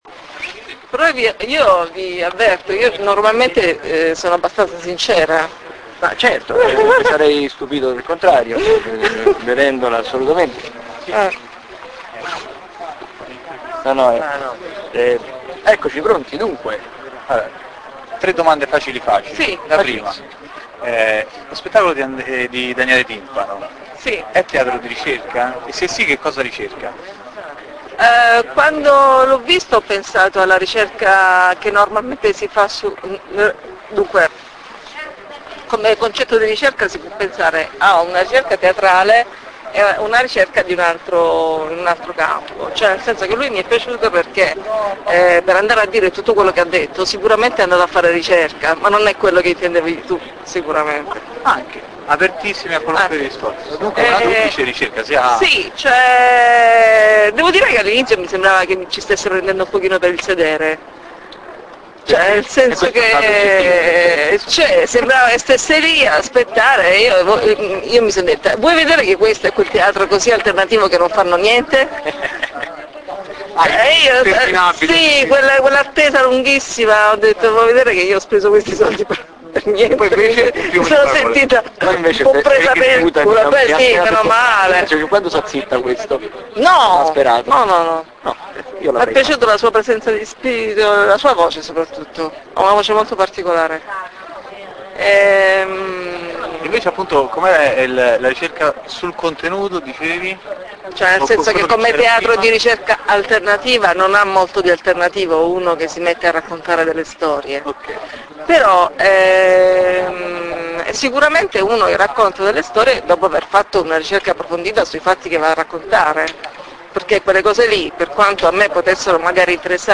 Ogni sera intervisteremo il pubblico porgendogli tre domande sugli spettacoli.